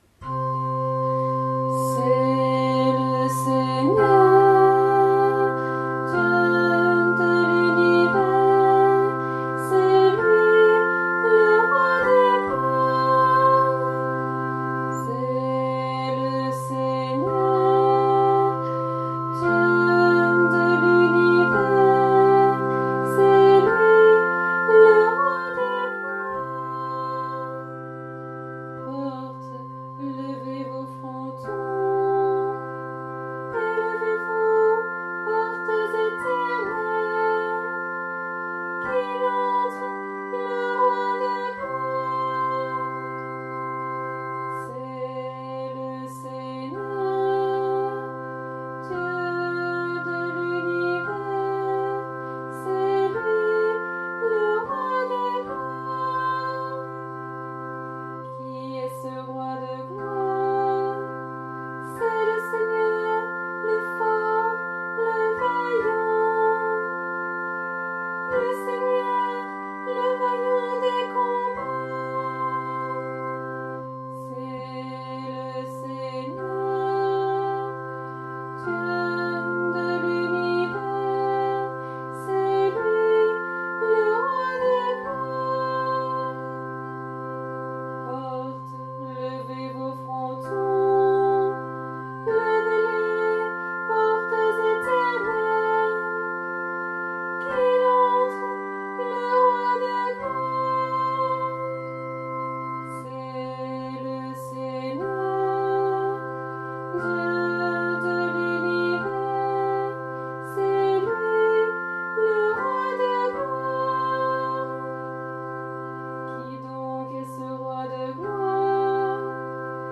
Antienne pour la Fête de la Présentation du Seigneur au Temple (année C)